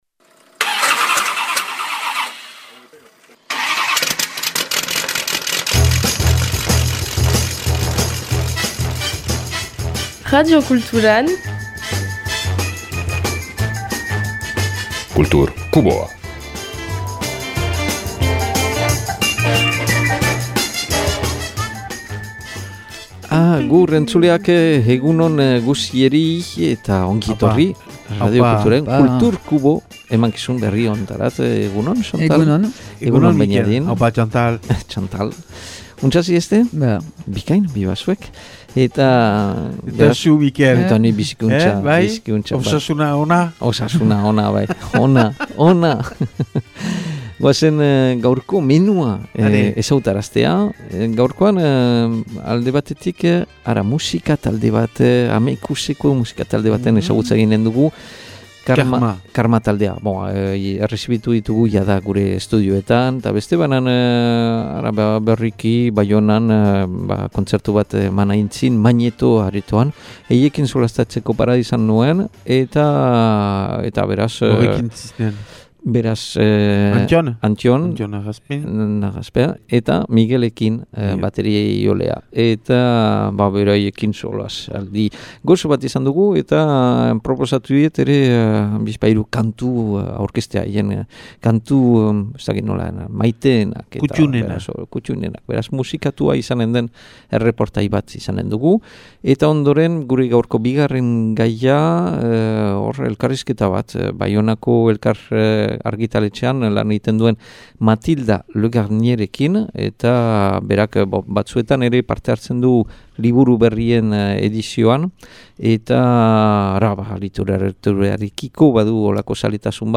Aste honetako Kultur Kuboan : Karma taldea 2022an sortu zen estilo ugarietako eskaintza berezi batekin, betiere rock musikaren inguruan. 2023an « Odolean Dena » diska aurkeztu eta publikoaren onezpena izan ondoren, 2025. urtean « Dirudienez » deitutako lan berria kaleratu dute. Hirukote honetako bi partaideekin solastatzeko parada izan dugu.